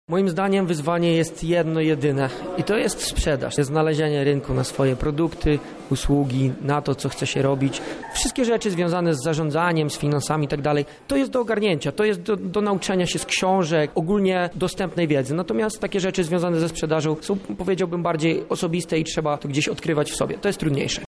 Eksperci z całego świata rozmawiali na KUL o przedsiębiorczości